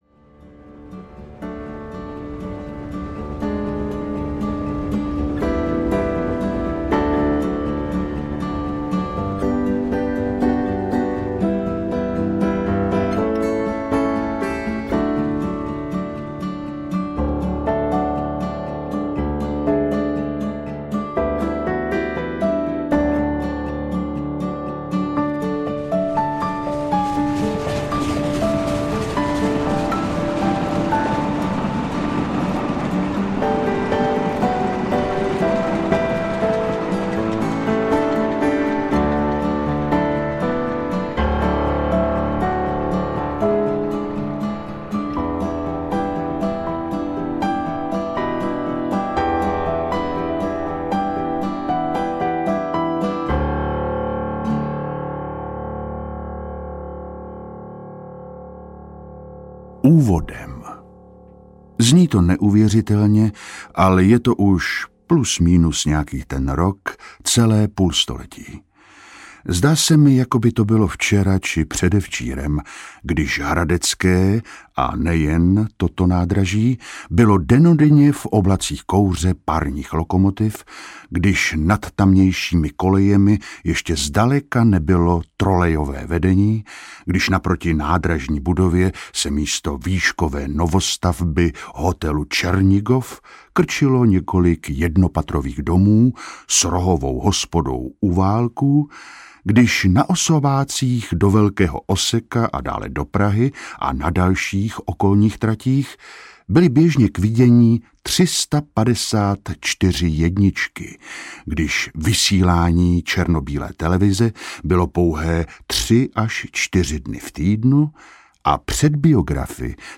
Příběhy Eléva audiokniha
Ukázka z knihy